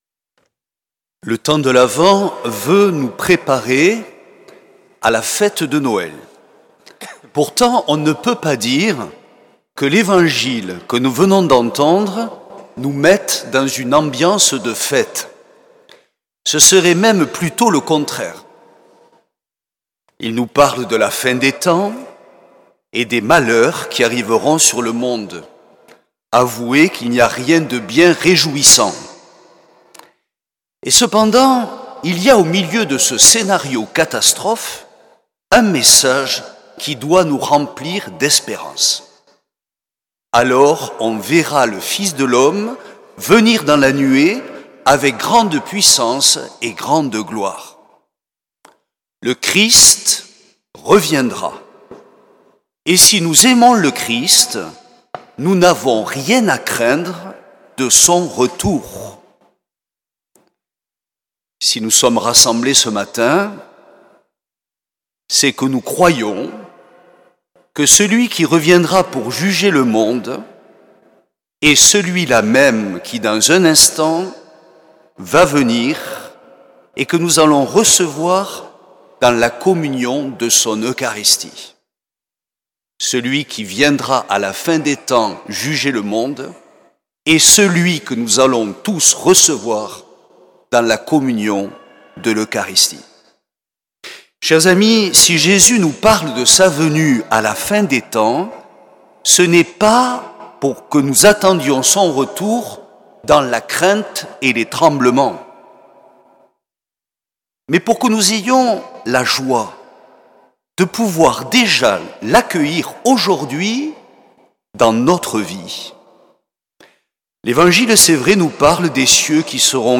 Homélie
1er dimanche de l’Avent